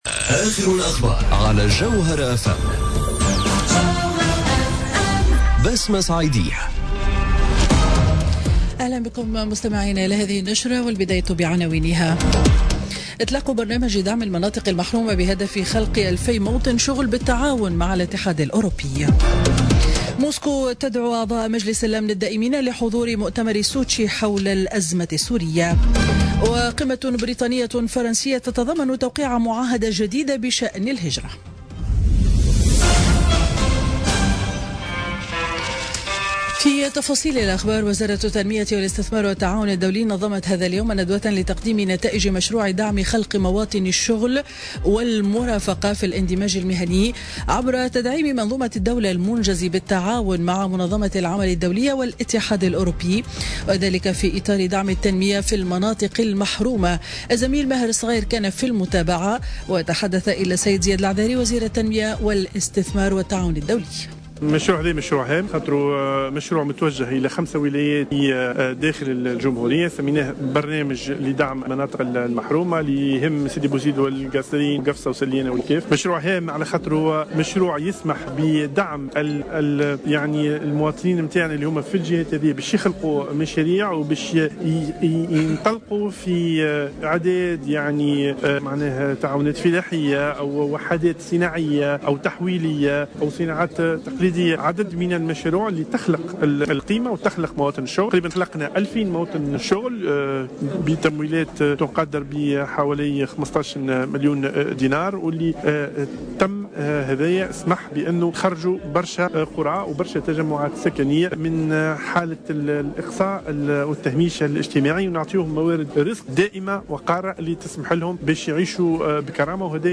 Journal Info 12h00 du jeudi 18 Janvier 2018